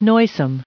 added pronounciation and merriam webster audio
997_noisome.ogg